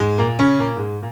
keys_14.wav